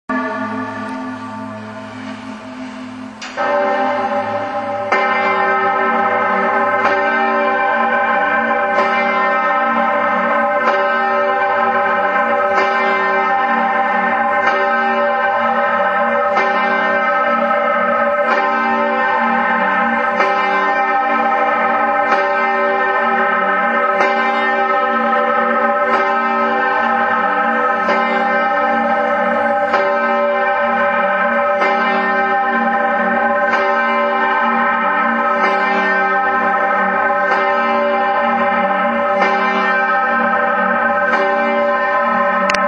Herz-Jesu-Glocke - Die Glocken der Pfarrkirche Marling